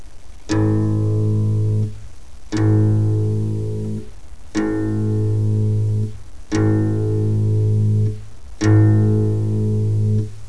El sonido de cada cuerda afinada deberia de sonar asi:
afinacion_la.wav